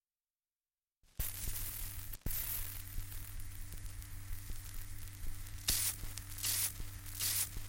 Dialect recording in Wall, Northumberland
78 r.p.m., cellulose nitrate on aluminium